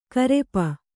♪ karepa